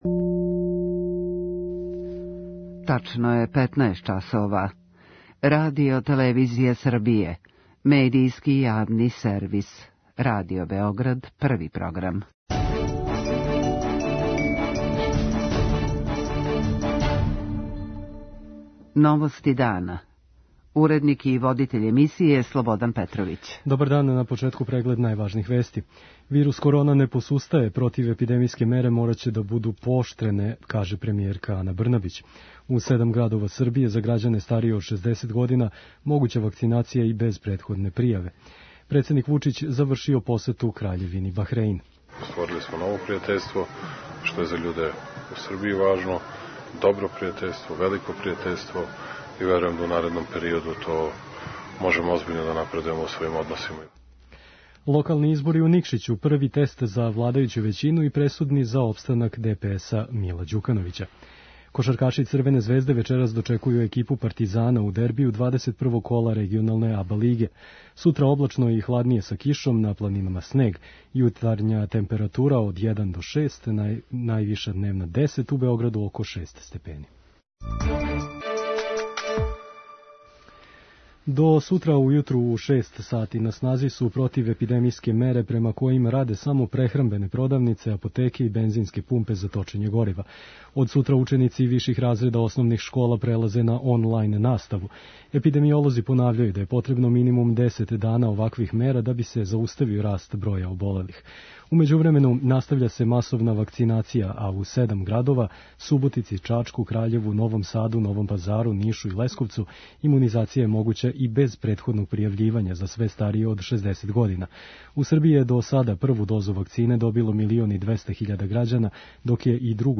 Најавила је да ће у току дана имати састанак са медицинским делом Кризног штаба, нагласивши да ће мере морати да буду пооштрене. преузми : 6.39 MB Новости дана Autor: Радио Београд 1 “Новости дана”, централна информативна емисија Првог програма Радио Београда емитује се од јесени 1958. године.